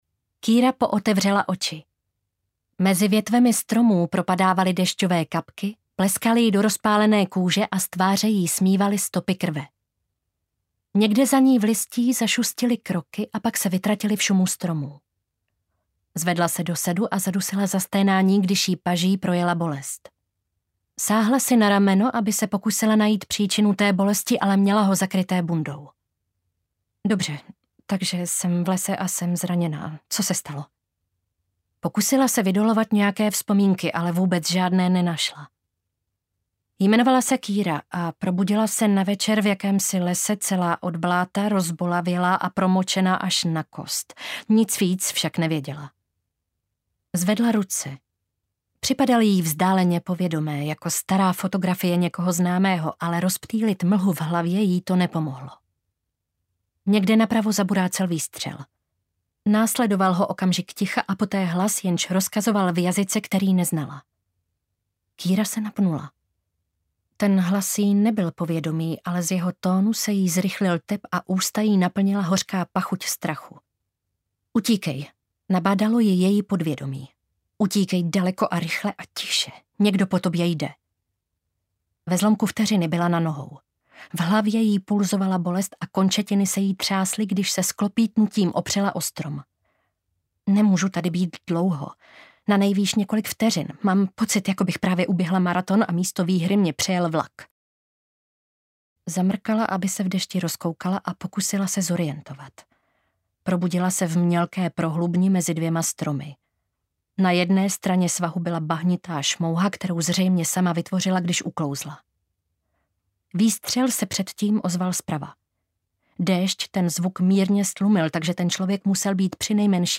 Šeptající mrtví audiokniha
Ukázka z knihy